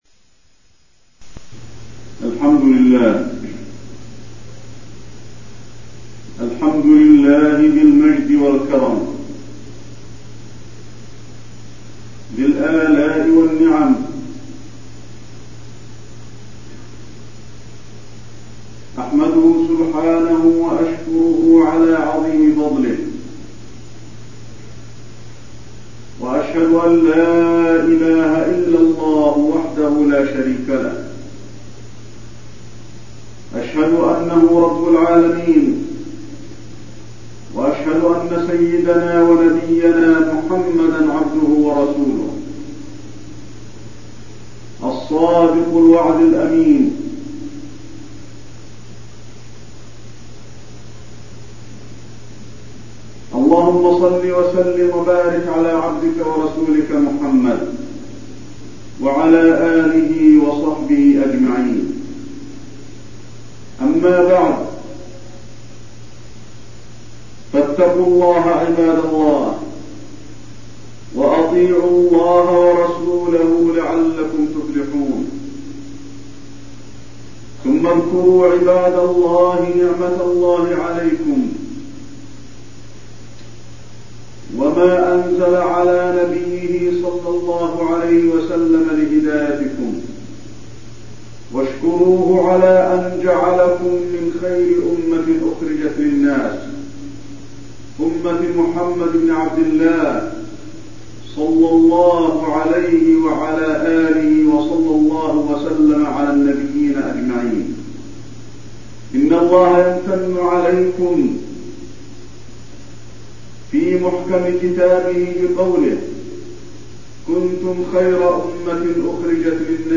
خطبة خير أمة أخرجت للناس وفيها: تفضيل هذه الأمة على سائر الأمم، صفات هذه الأمة، وأنه ليس بين العبد وربه إلا الإيمان والعمل الصالح
تاريخ النشر ١٢ ربيع الأول ١٤٠٧ المكان: المسجد النبوي الشيخ: فضيلة الشيخ د. علي بن عبدالرحمن الحذيفي فضيلة الشيخ د. علي بن عبدالرحمن الحذيفي خير أمة أخرجت للناس